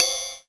HAT OPEN.wav